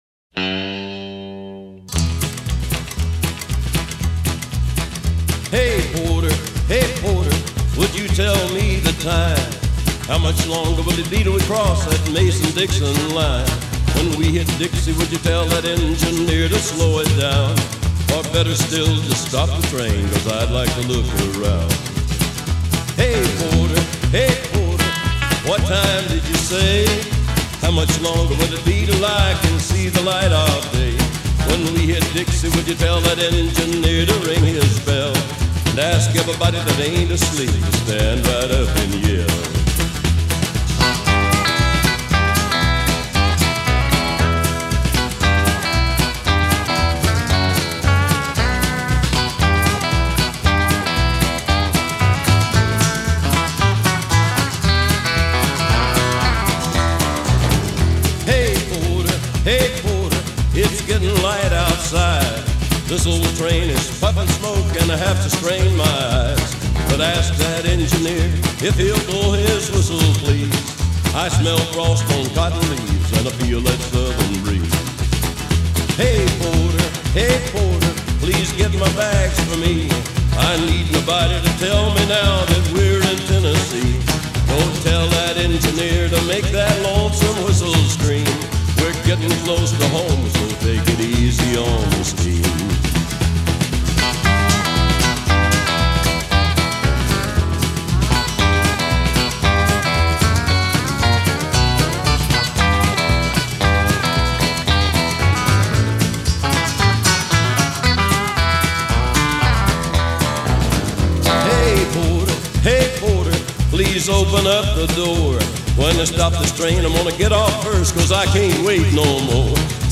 Жанр: Country, Rock